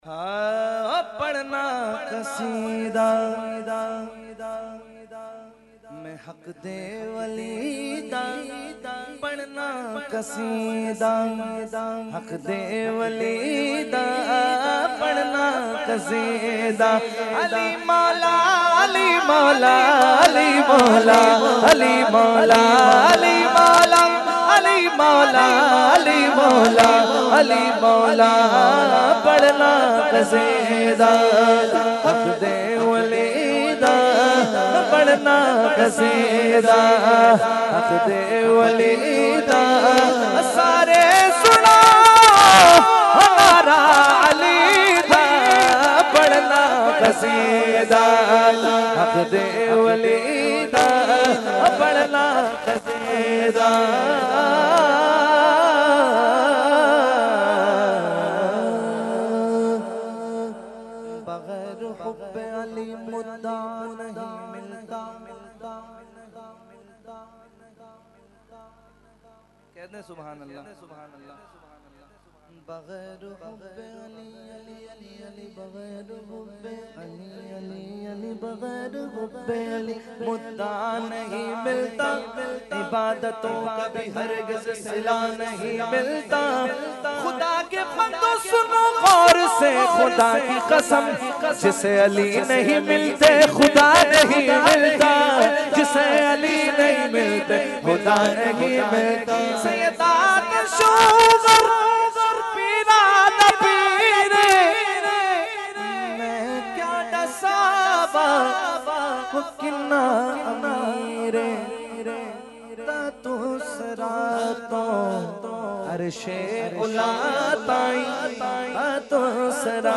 Category : Manqabat | Language : UrduEvent : Urs Ashraful Mashaikh 2019